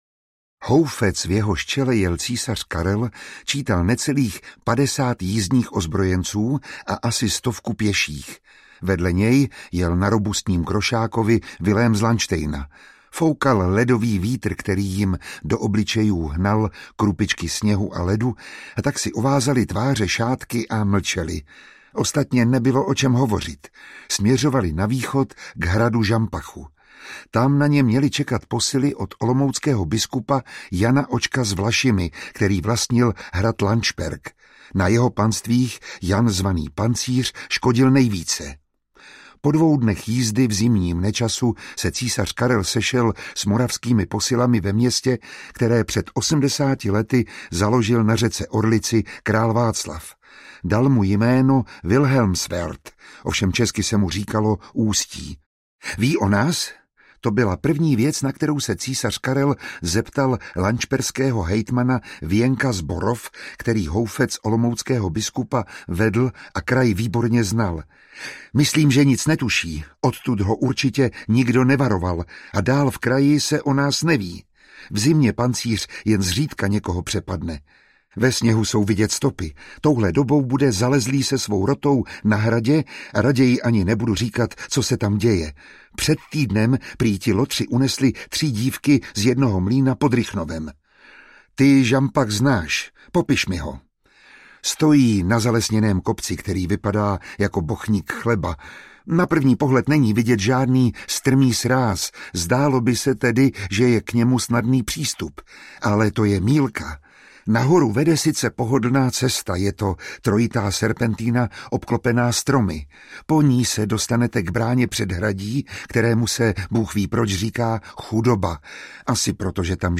Ukázka z knihy
Čte Miroslav Táborský.
Vyrobilo studio Soundguru.